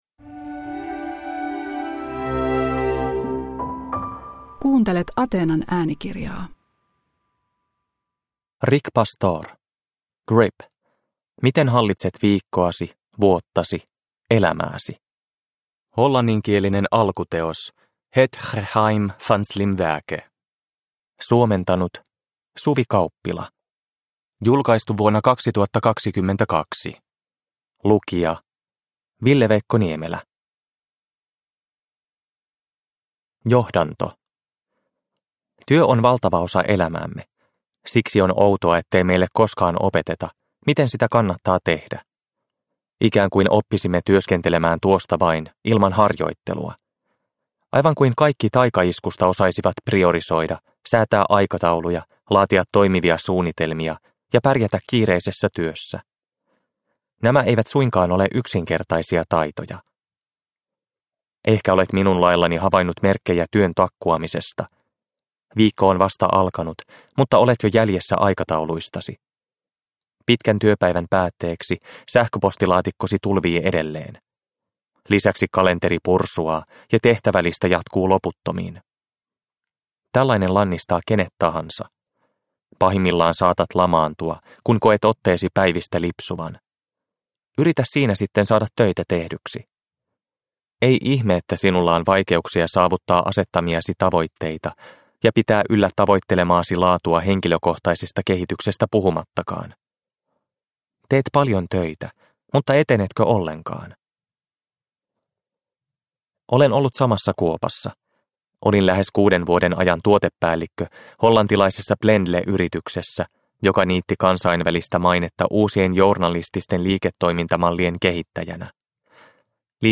Grip. Miten hallitset viikkoasi, vuottasi, elämääsi – Ljudbok – Laddas ner